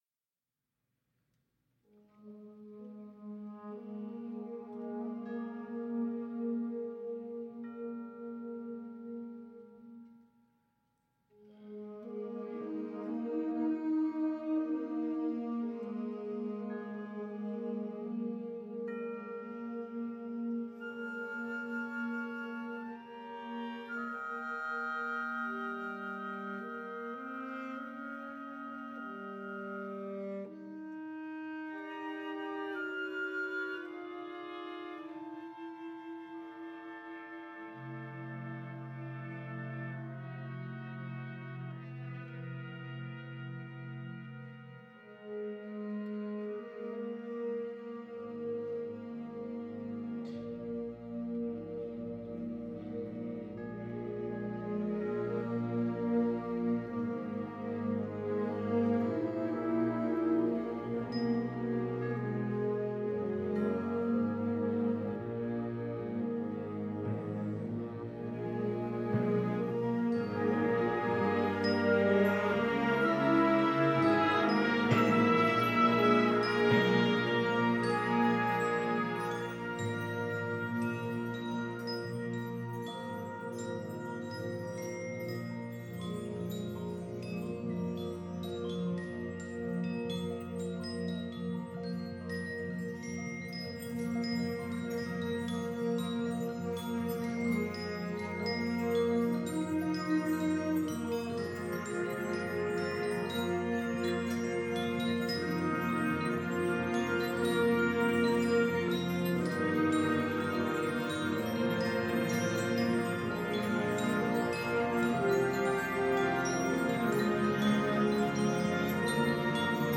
編成：吹奏楽
Orchesta Bells, Vibraphone
Tubular Bells, Xylophone